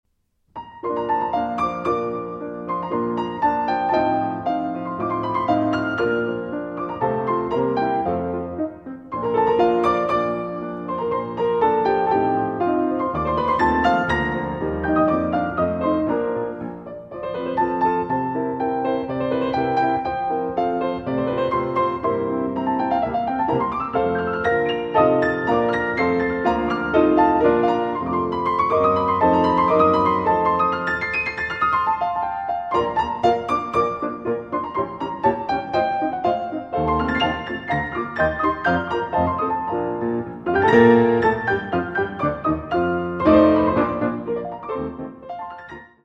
for piano duet